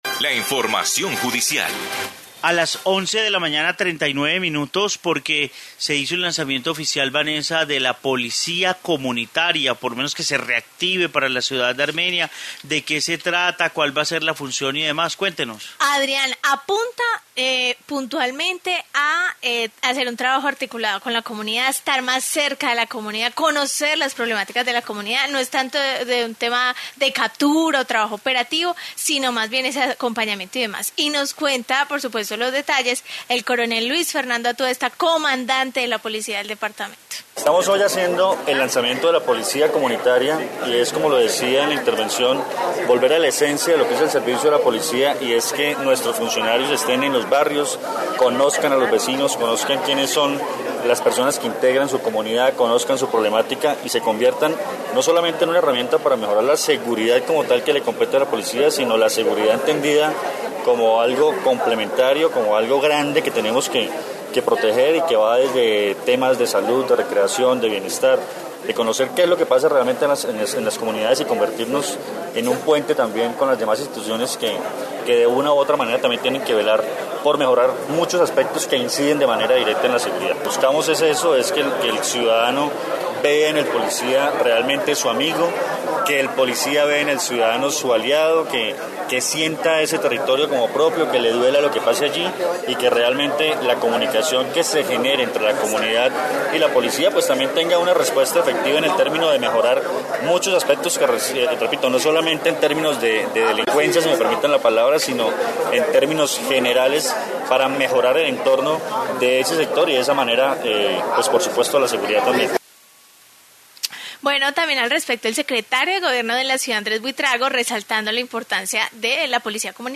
Informe policía comunitaria